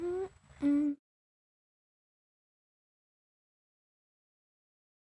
标签： speak talk my voice girl english female vocal speech american talking creepy god oh words woman